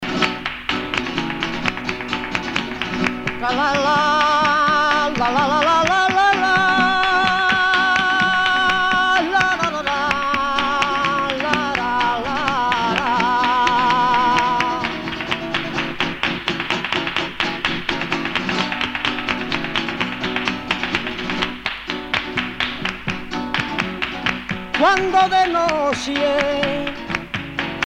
danse : flamenco
Pièce musicale éditée